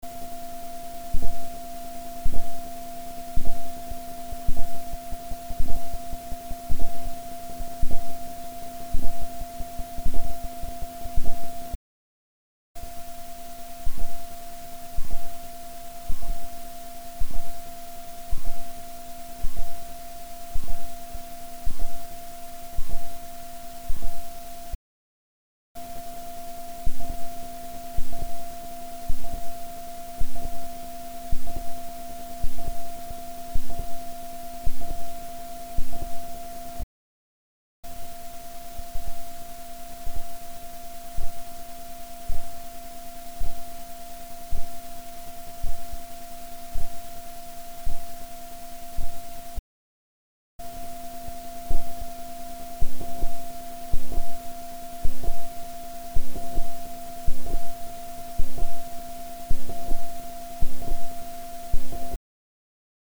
SDカードの書込動作にシンクロした小さなノイズ
目立って聴こえるのは同時に中音域に付随する小さなノイズのようだ．
乾電池駆動，ライン入力，ゲインHで無音状態を録音したファイルを
PC側で48dB増幅して，ノイズを聴きやすく拡大したもの．
の順番で約10秒ずつ並べた．各ノイズの波形は次のようなもの．
sd_noise_catalog.mp3